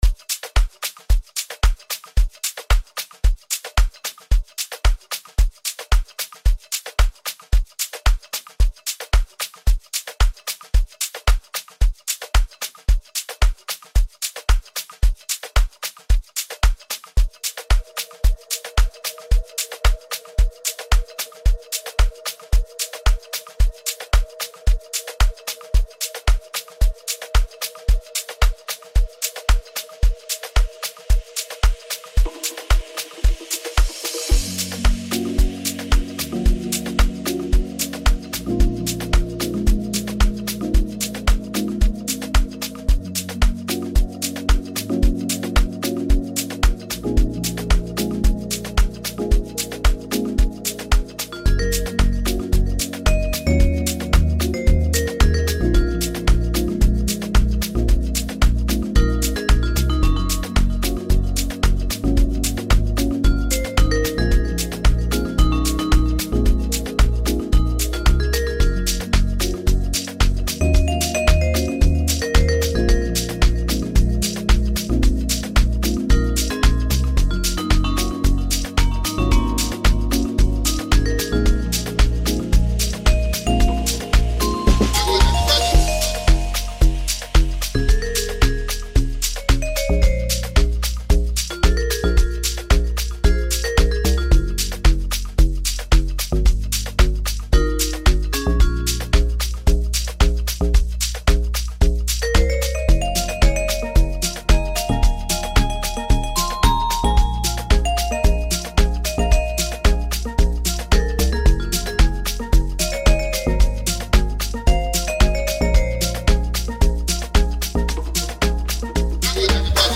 • Genre: Amapiano